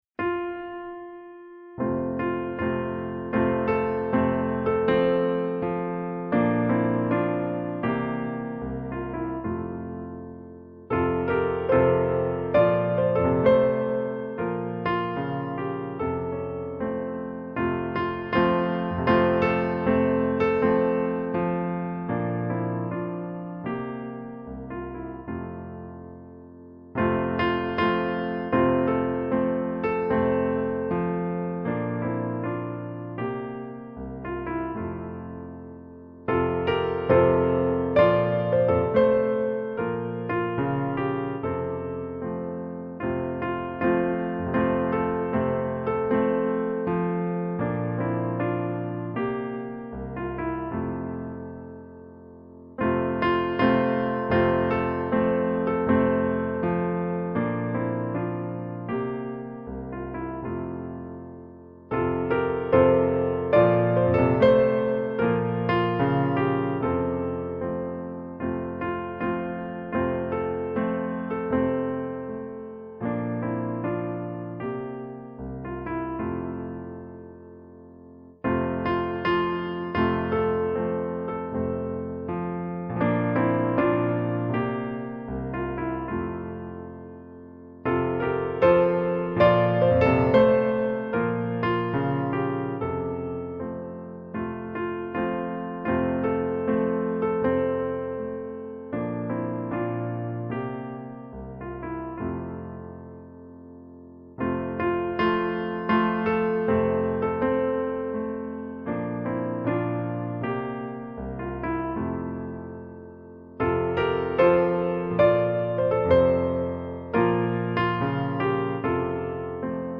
(Arab Desert Song)